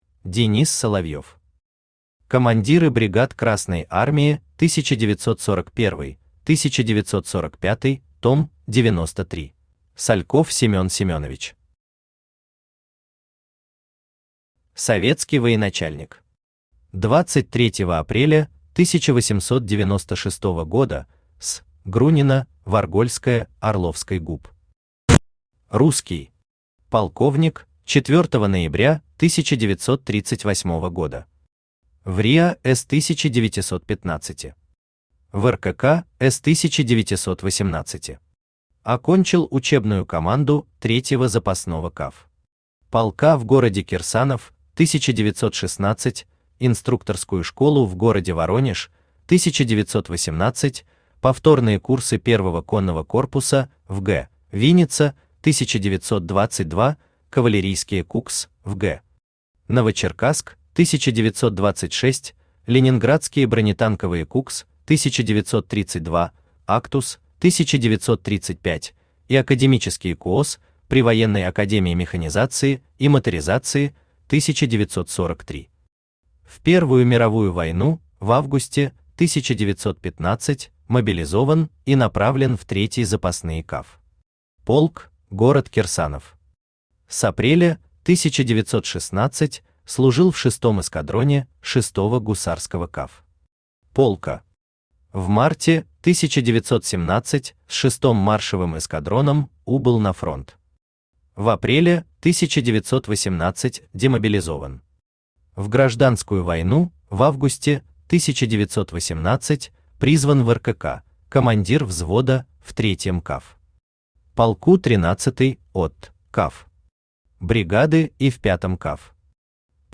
Aудиокнига Командиры бригад Красной Армии 1941-1945 Том 93 Автор Денис Соловьев Читает аудиокнигу Авточтец ЛитРес.